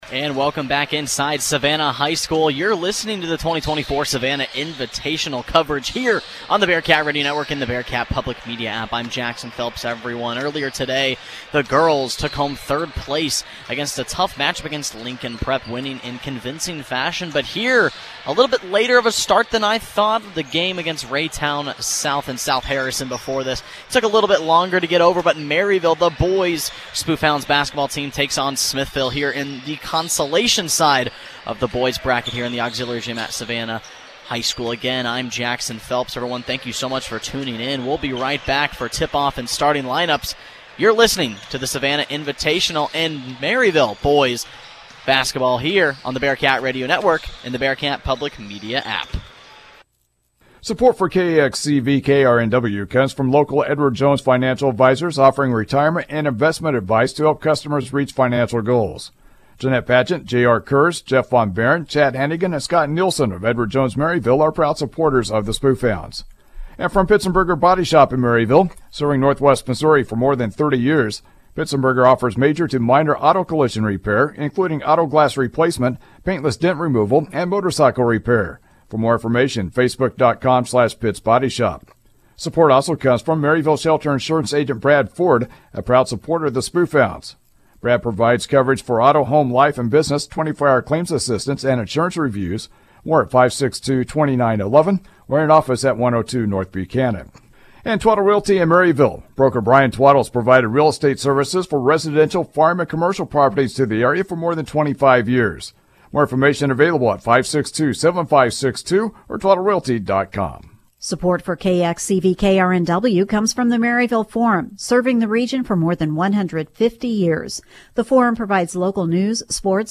Local Sports
Game | Basketball